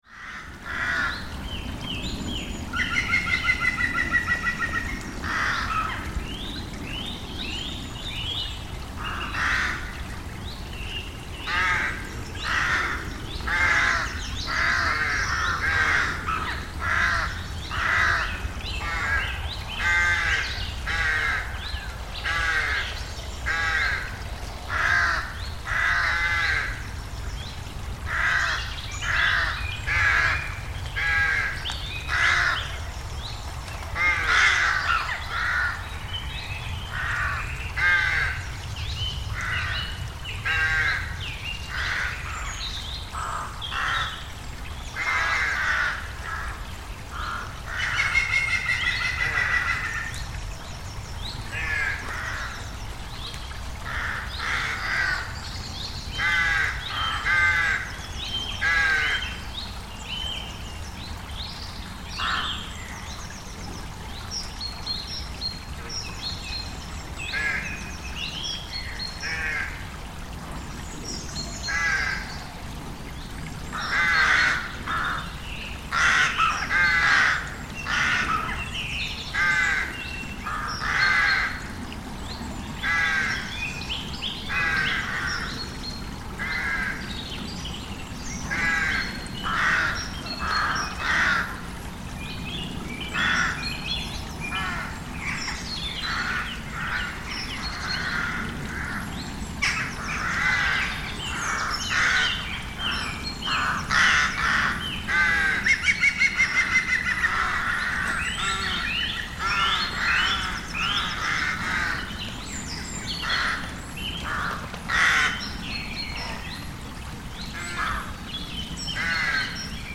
Crows in Chilswell Valley
In a valley in a nature reserve in Oxford near sunset, crows are arguing loudly in the trees above us as they prepare to nest for the night - we can also hear the alarm calls of a woodpecker in the same woods. To our left, the rushing of a small stream continues throughout.